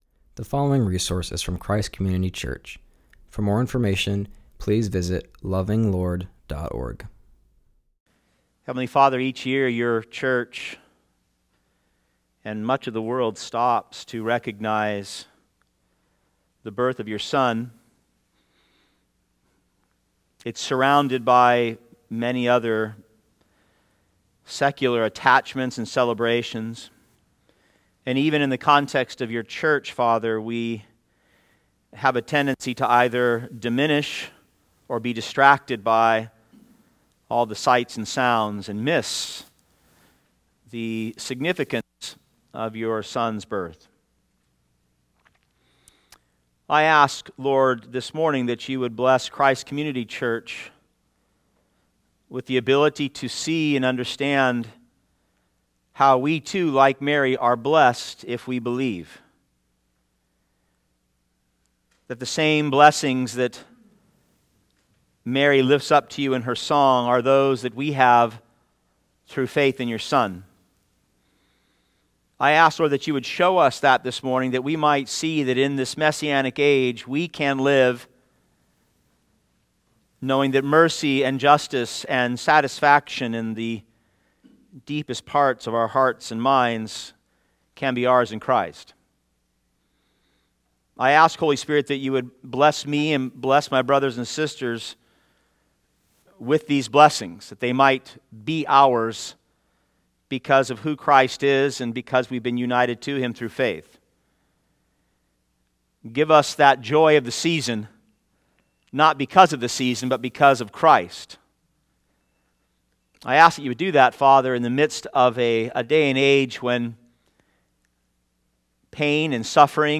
preaches from Luke 1:39-52.